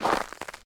pedology_ice_white_footstep.1.ogg